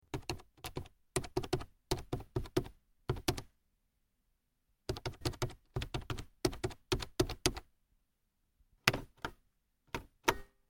جلوه های صوتی
دانلود آهنگ تایپ 5 از افکت صوتی اشیاء
دانلود صدای تایپ 5 از ساعد نیوز با لینک مستقیم و کیفیت بالا